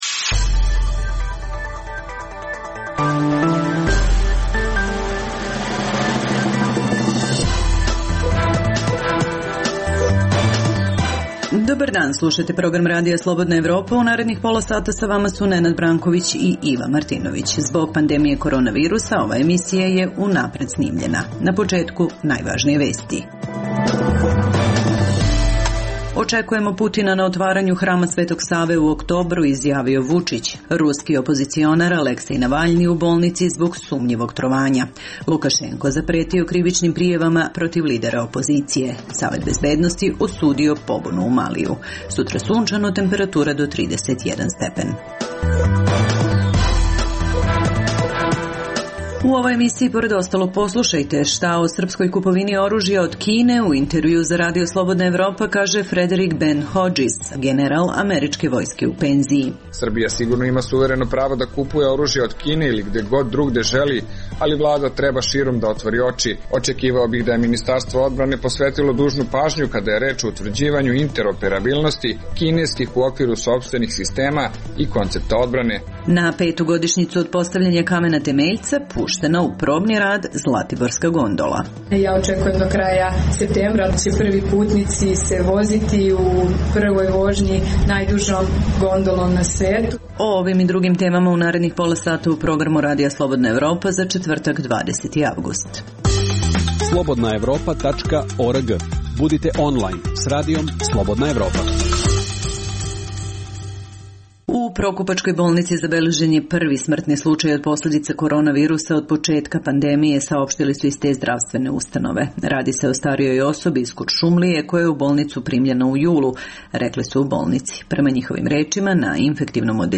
U ovoj emisiji, pored ostalog, poslušajte šta o srpskoj kupovini oružja od Kine u intervjuu za RSE kaže Frederik Ben Hodžis, general američke vojske u penziji. Na petogodišnjicu od postavljanja kamena temeljca puštena u probni rad zlatiborska gondola.